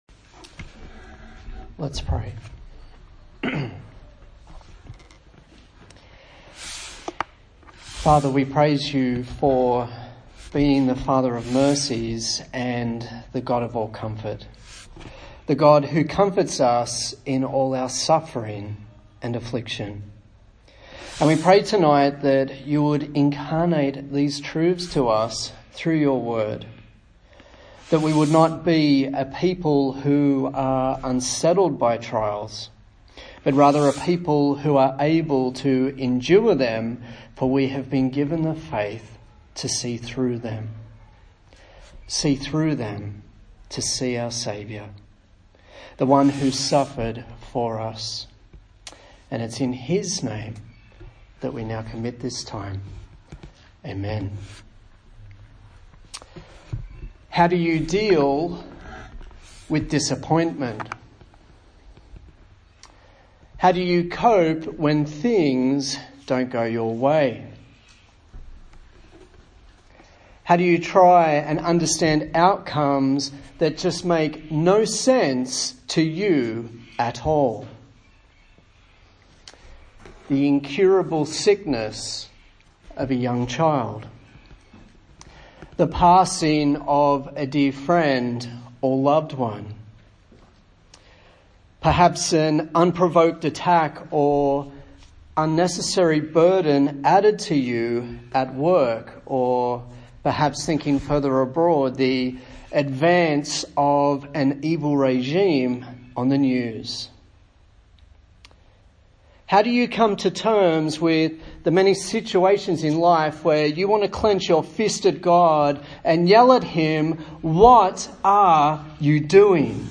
A sermon in the series on the book of 1 Thessalonians